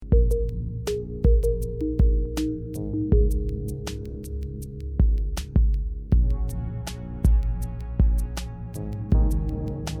2. Ambient-Techno (Musik)